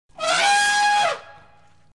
Trumpet Sound Of An Elephant Téléchargement d'Effet Sonore